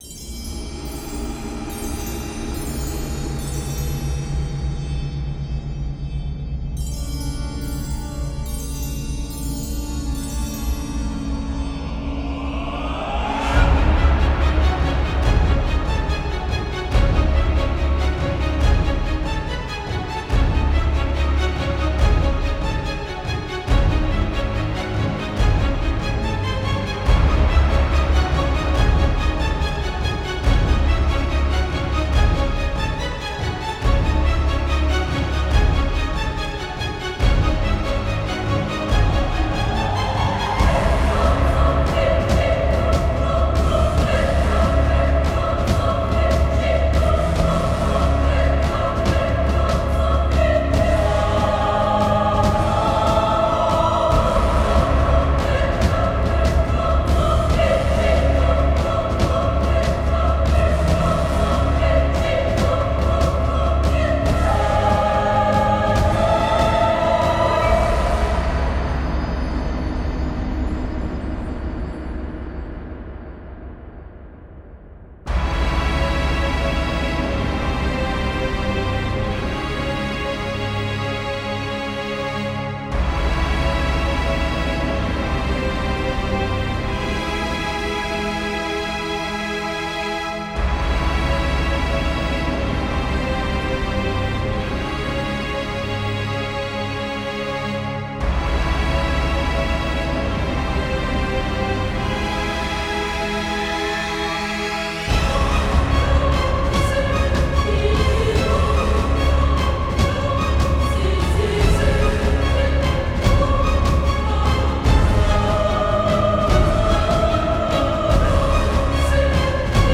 Dark souls type boss theme